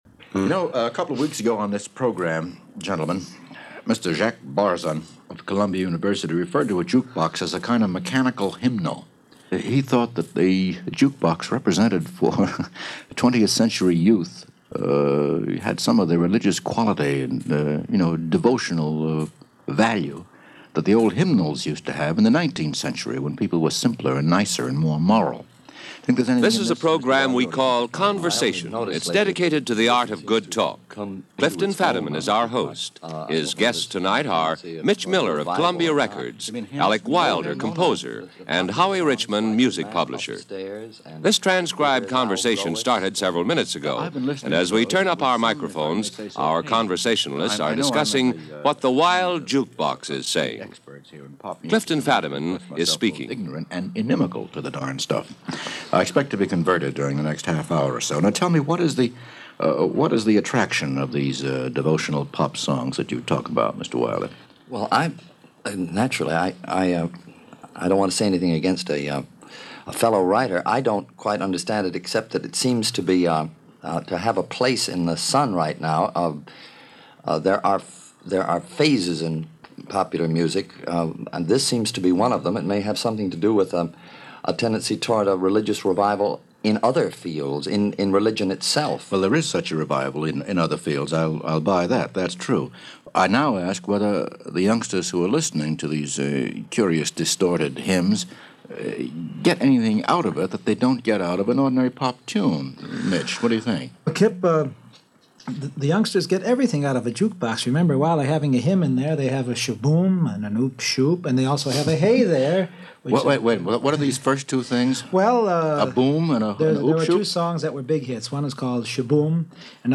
This radio program features a discussion between three members of the Music business. It was part of the Converstion series from NBC radio and it was originally broadcast on February 15, 1955.